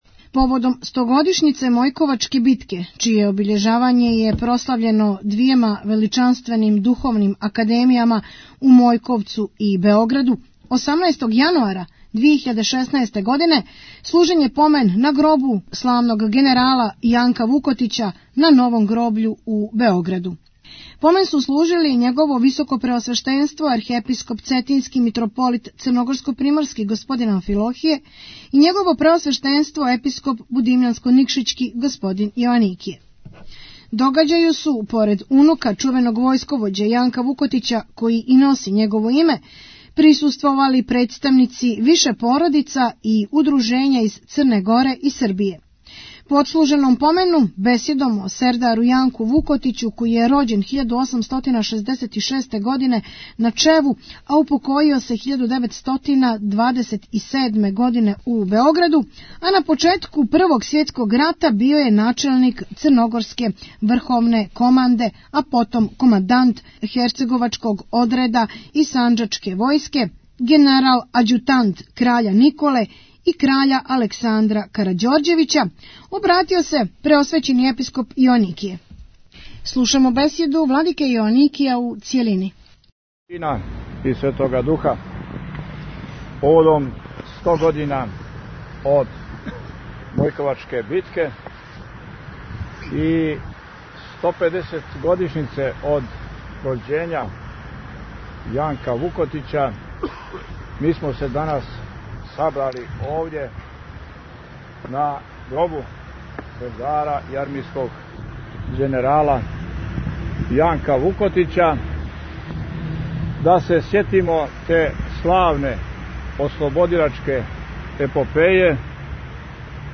Поводом стогодишњице Мојковачке битке, чија обиљежавања је прослављено двјема величанственим духовним академија у Мојковцу и Београду, 18.јануара 2016, одслужен је помен на гробу славног генерала Јанка Вукотића на Новом гробљу у Београду.
Бесједу Владике Јоаникија преносимо у цјелости.